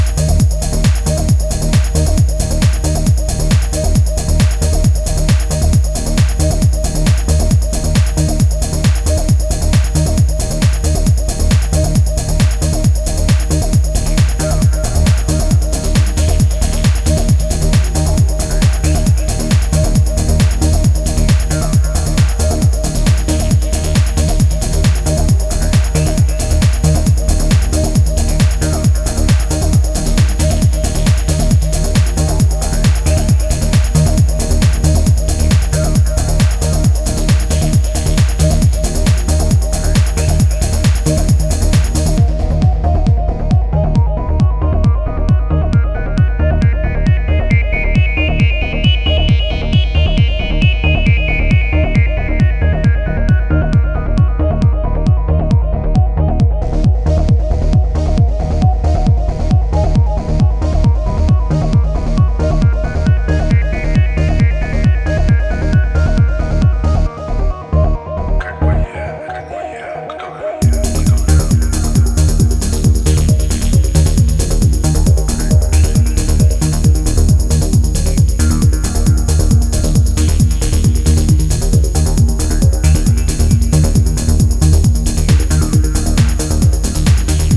Neo Goa / Progressive Trance tracks
aery Drum’n’bass experimentations